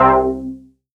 JUNO SEQ.wav